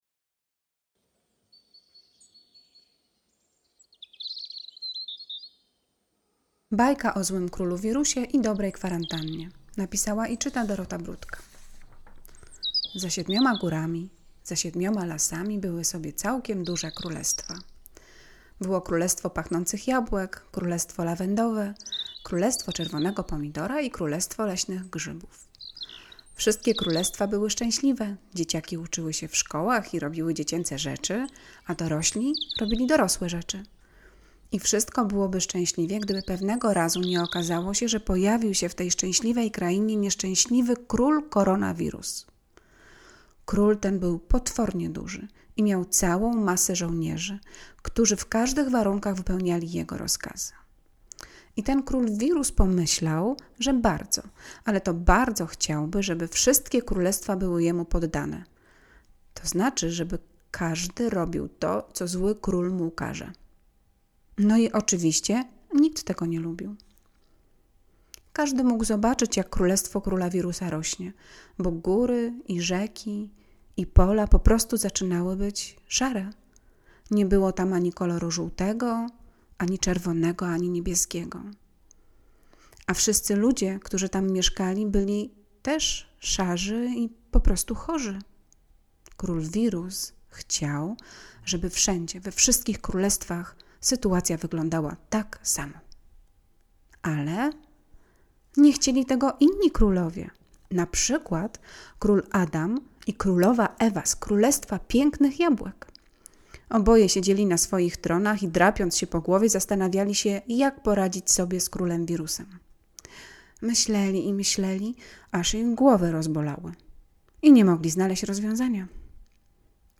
V Zdjęcia Załączniki BAJKA [264.59 kB] Bajka do wysłuchania [12.83 MB]
bajka_o_krolu_wirusie_do_wysluchania.mp3